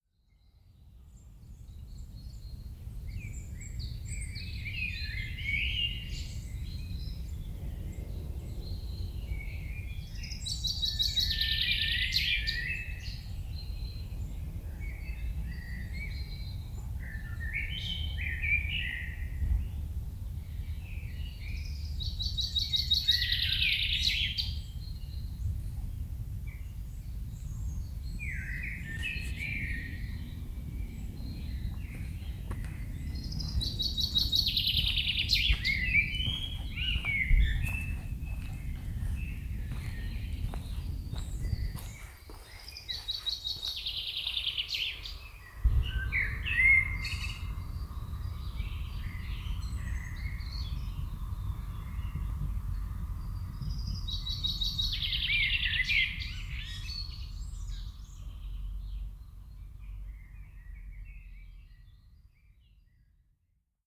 birds – suburban
birds-suburban.mp3